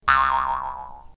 bounce2.ogg